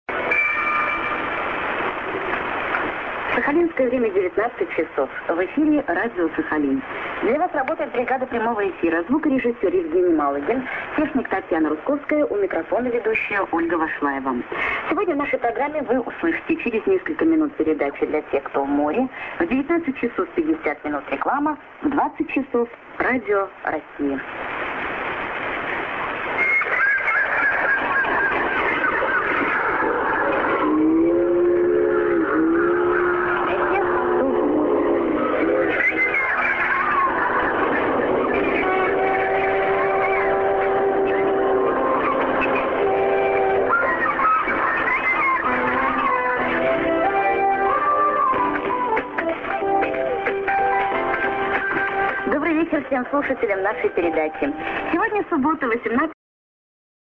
TS->ID(women)->　USB R.Sakharinsk(Radio Rossii)